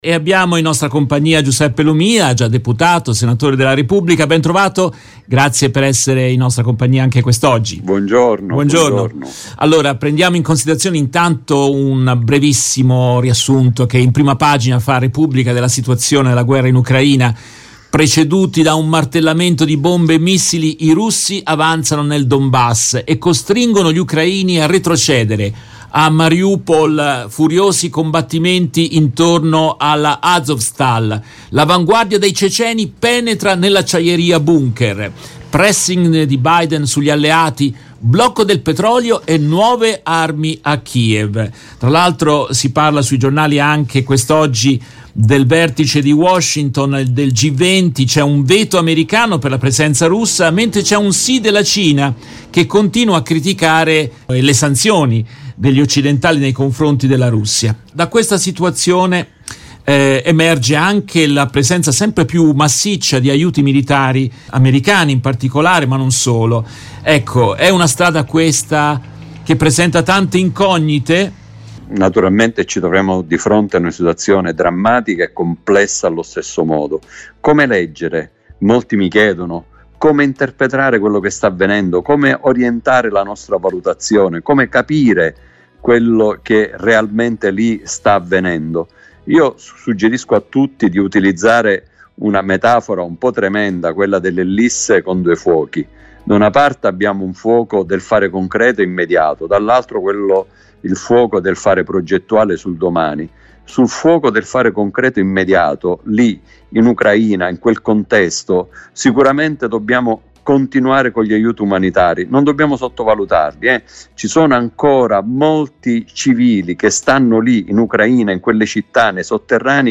In questa intervista tratta dalla diretta RVS del 20 aprile 2022